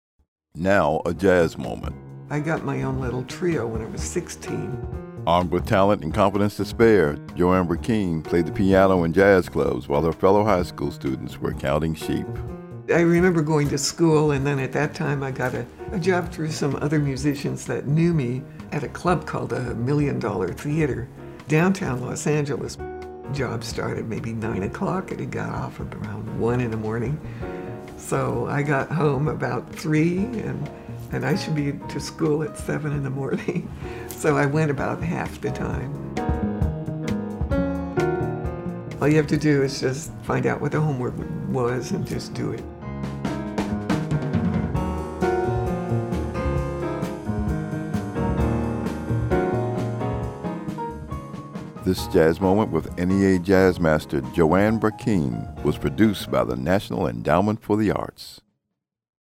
THIS JAZZ MOMENT WITH NEA JAZZ MASTER JOANNE BRACKEEN WAS CREATED BY THE NATIONAL ENDOWMENT FOR THE ARTS.
“Canyon” composed by Mark Levine and performed by Joanne Brackeen, from the album, Invitation, used courtesy of 1201 Music and by permission of Ethiopia Music, BMI.